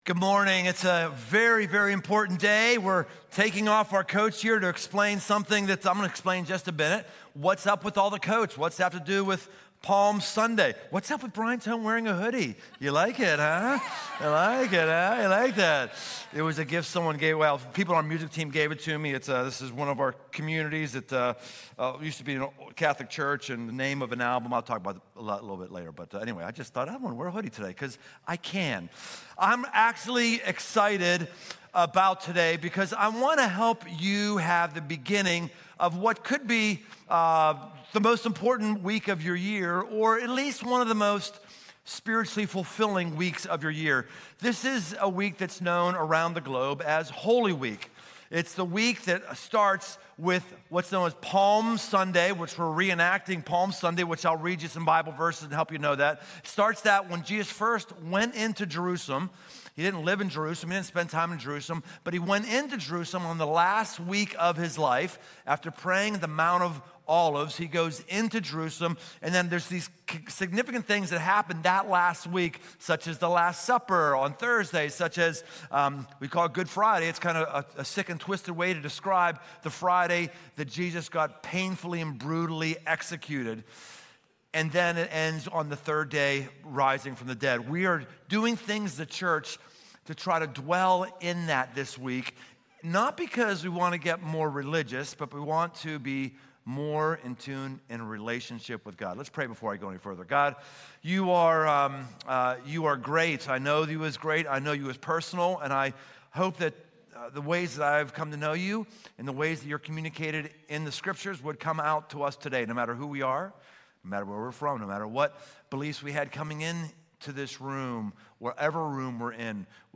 Holy Week kicks off with a worship-filled celebration focused on the arrival of the King. We talk about how to prepare our mind, schedule, and our posture for the coming days to get the most out of Holy Week.